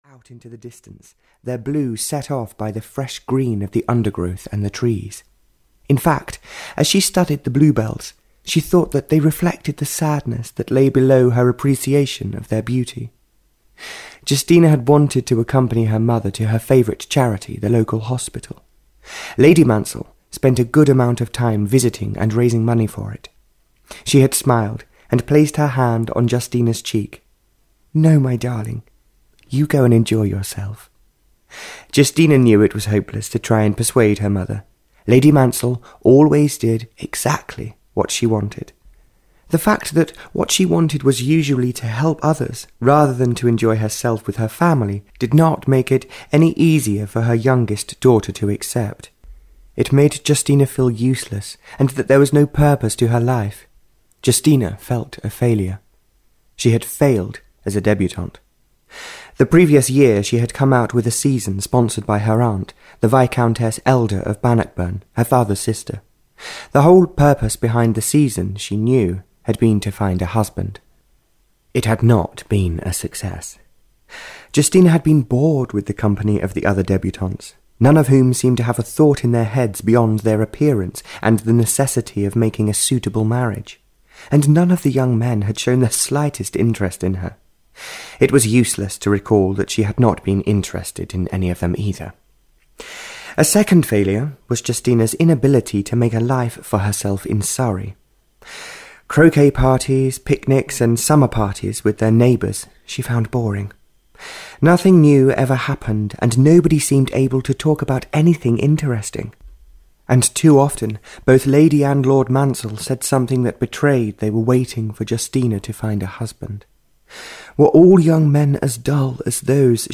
Audio knihaTouching the Stars (Barbara Cartland’s Pink Collection 35) (EN)
Ukázka z knihy